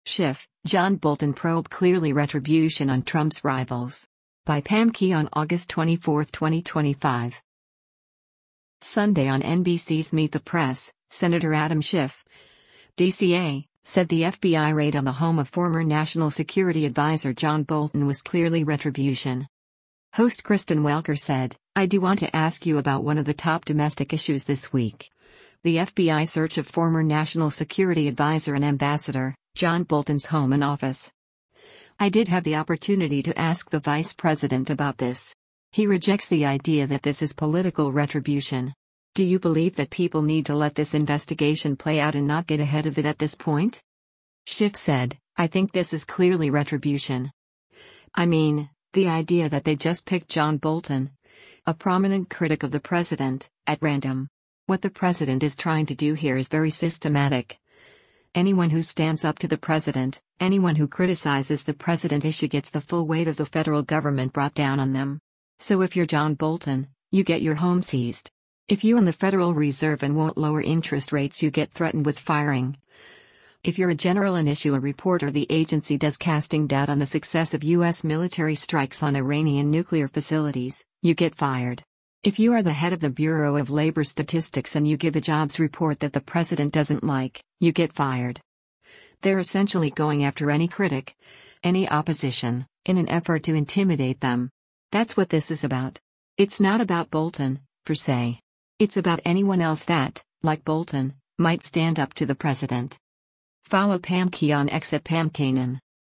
Sunday on NBC’s “Meet the Press,” Sen. Adam Schiff (D-CA) said the FBI raid on the home of former National Security Advisor John Bolton was “clearly retribution.”